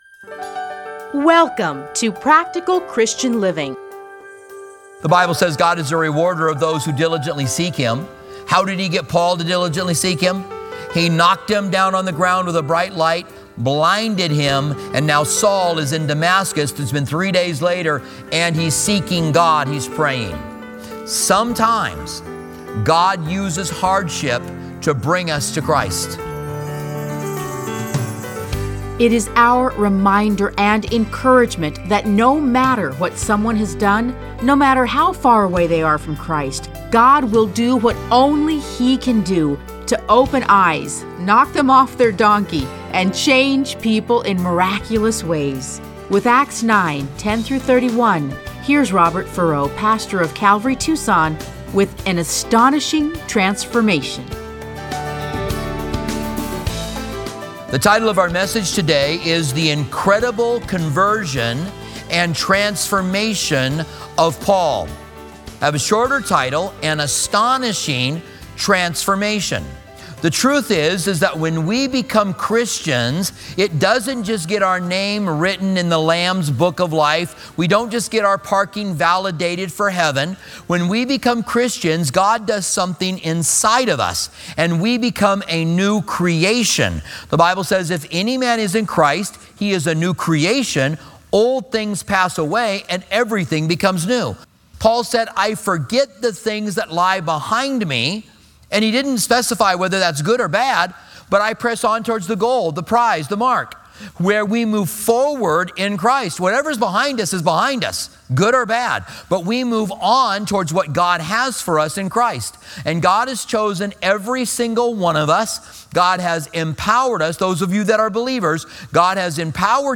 Listen to a teaching from Acts 9:10-31.